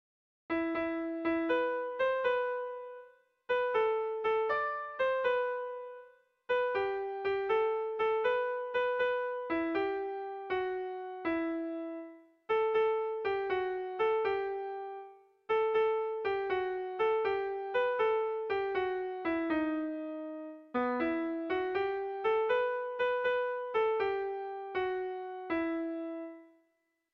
Tragikoa
Bederatzikoa, txikiaren moldekoa, 6 puntuz (hg) / Sei puntukoa, txikiaren moldekoa (ip)